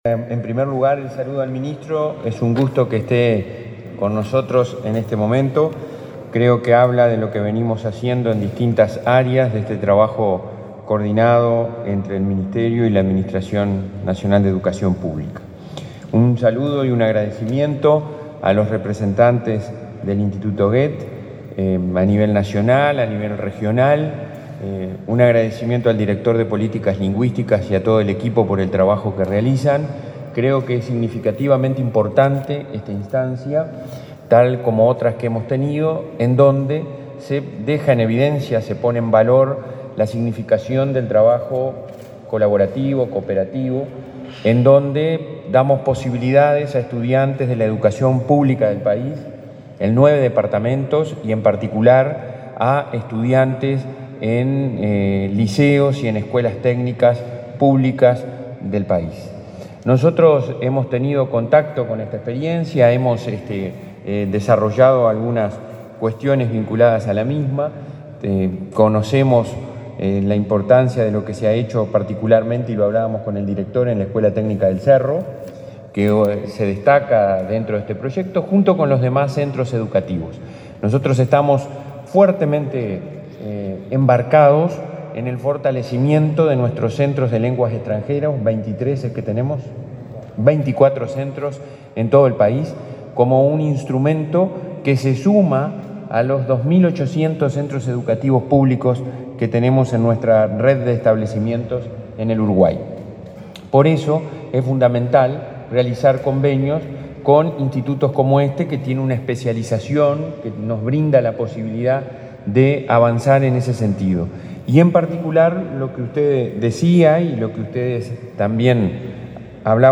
Palabras del presidente del Codicen y el ministro de Educación y Cultura
El presidente del Consejo Directivo Central (Codicen) de la Administración Nacional de Educación Pública (ANEP), Robert Silva, y el ministro de Educación y Cultura, Pablo da Silveira, participaron este viernes 3 en la presentación de los resultados de la enseñanza de alemán en educación media, por parte de la ANEP y el instituto Goethe.
silva_dasilveira.mp3